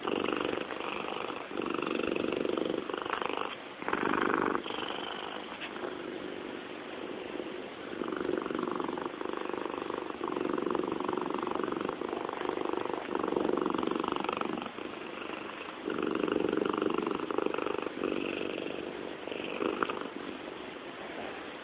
打呼噜的猫
描述：你的环境音轨需要甜美的猫咪的柔和呼噜声吗？
Tag: 自然声 小猫 呼噜声 呼噜声 现场录音